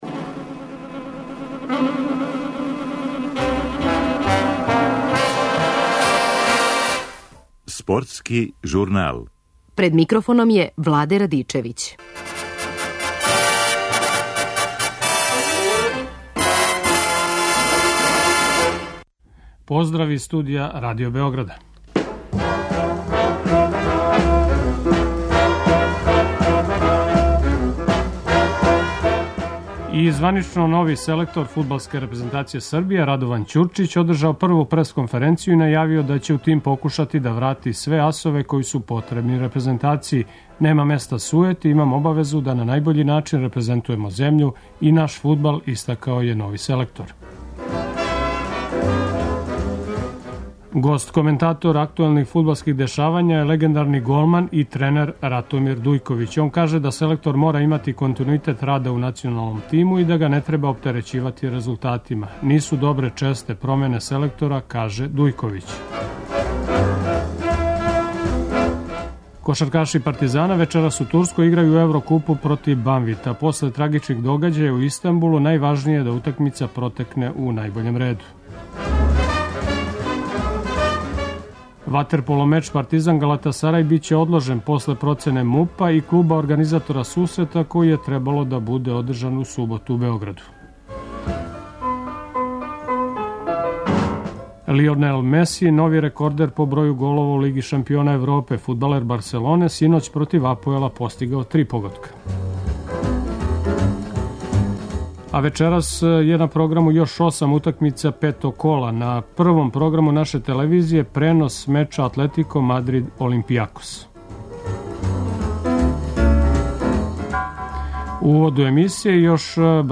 Гост коментатор актуелних фудбалских дешавања је легендрани голман и тренер Ратомир Дујковић. Он каже да селектор мора имати континуитет рада у националном тиму и да га не треба оптерећивати резултатима.